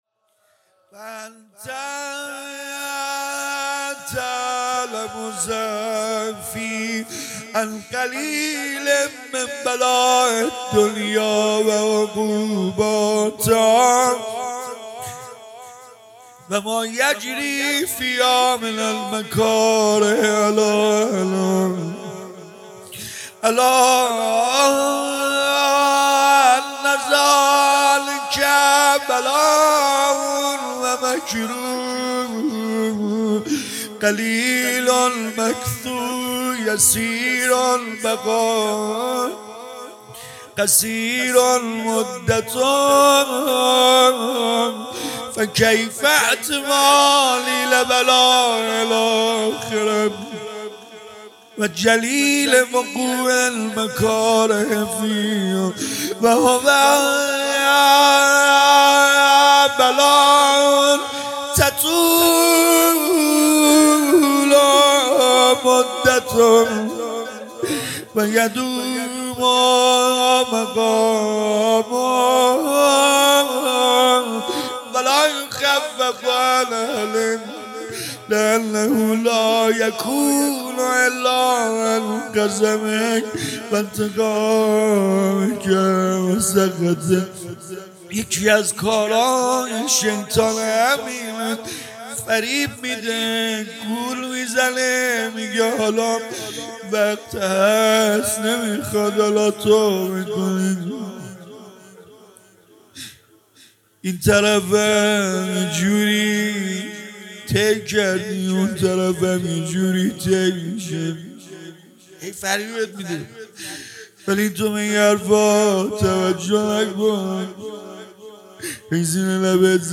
♦مراسم پر فیض دعای کمیل سحرگاه جمعه